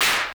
CLAP - IDOLS.wav